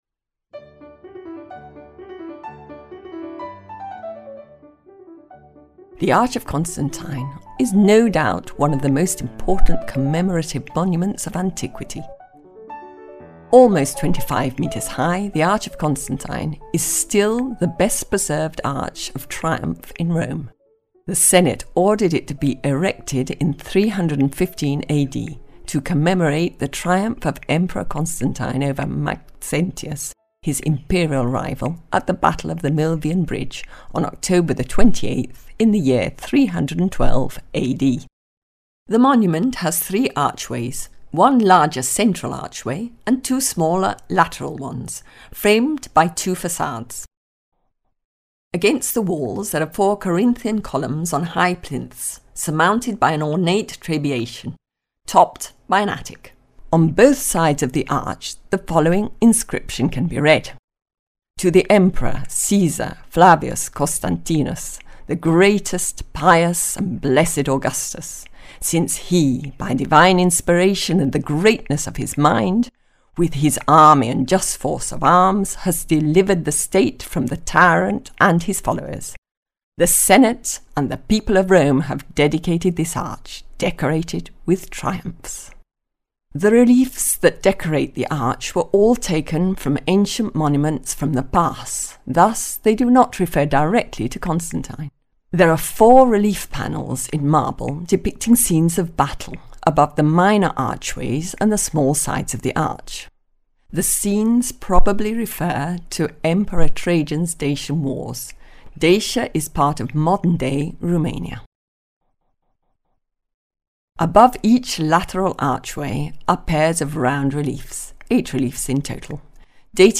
Audio Guide – Arch of Constantine